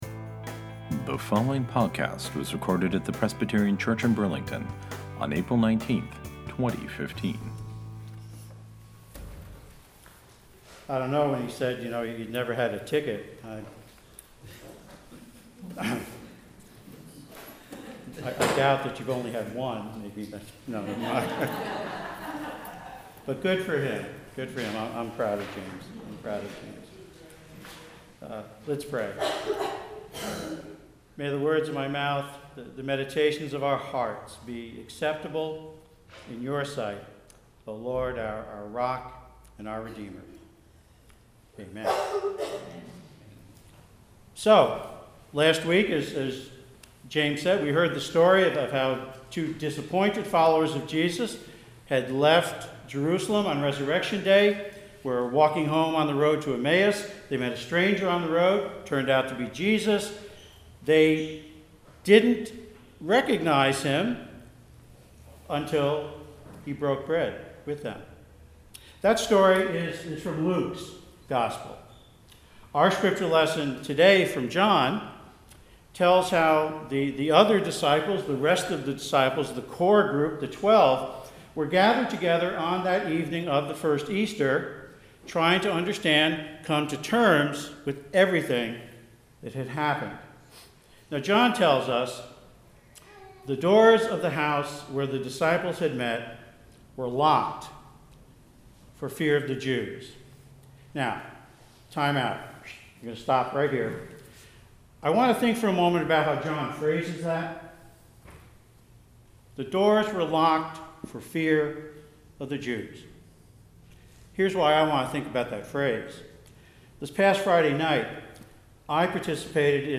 Here’s the sermon from Sunday, April 19…